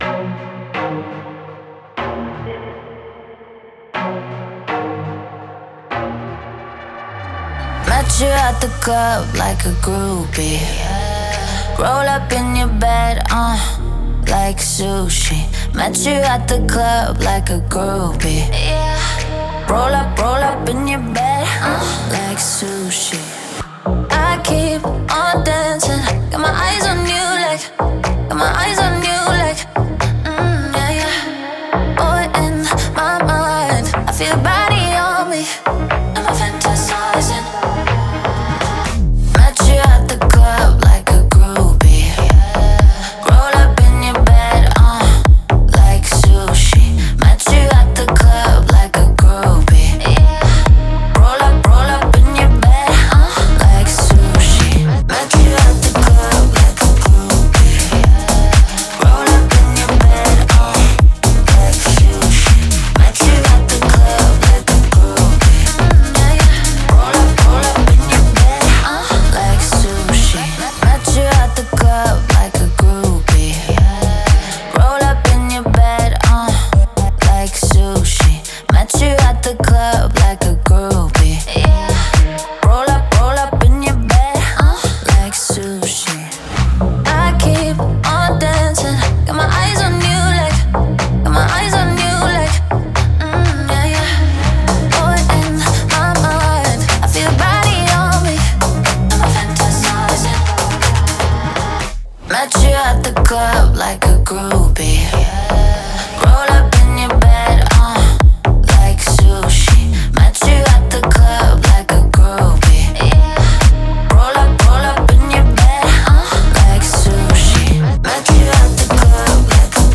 энергичная электронная танцевальная композиция
которая объединяет в себе элементы EDM и поп-музыки.